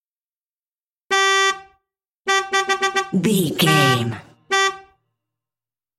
Car horn
Car horn 363
Sound Effects